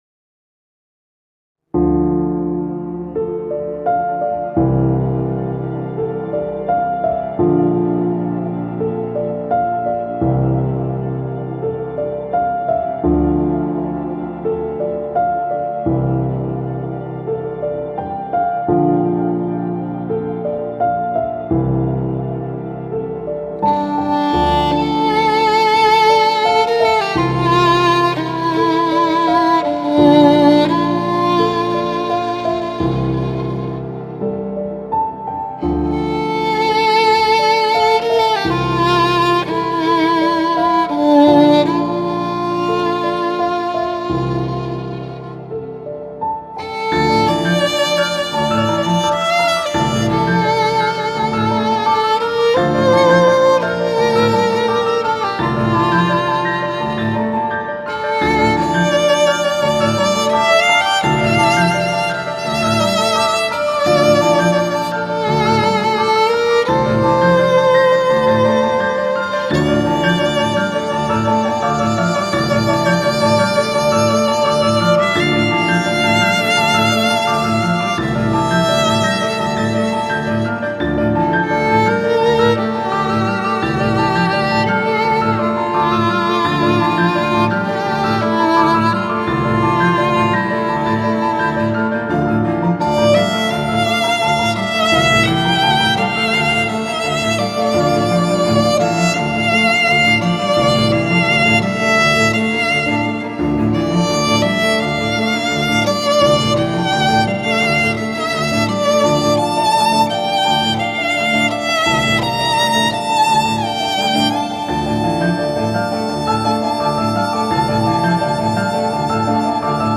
tema dizi müziği, duygusal hüzünlü heyecan fon müziği.